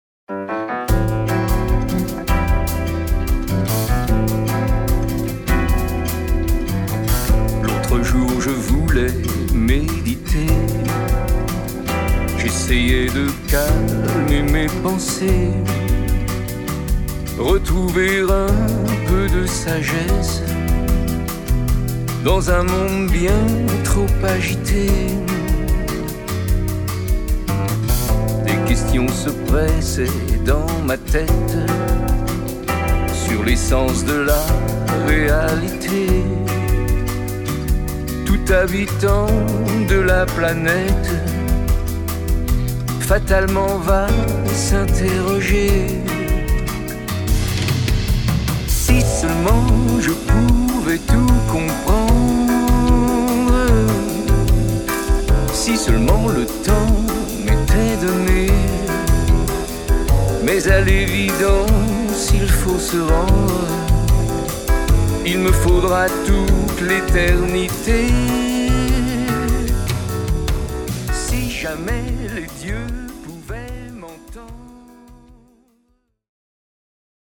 exprimées en ballades, jazz, bossa-novas, pop